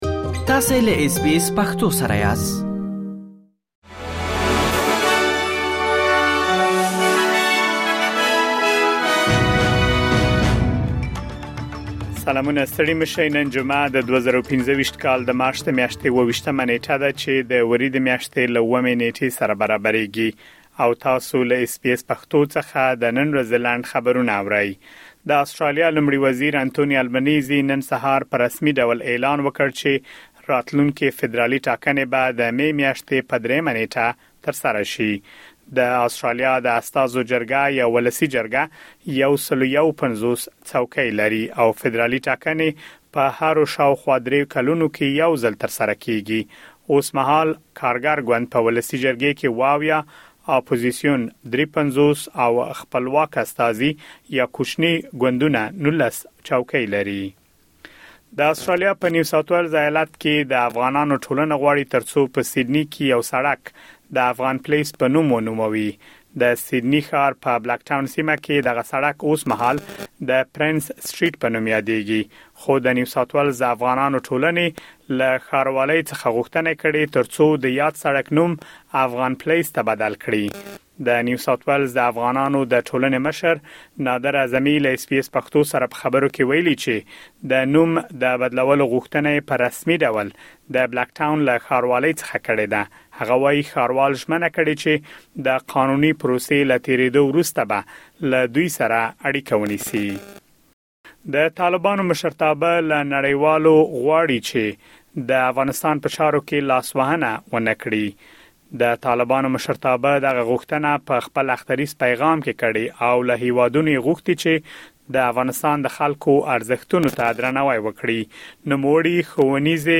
د اس بي اس پښتو د نن ورځې لنډ خبرونه | ۲۸ مارچ ۲۰۲۵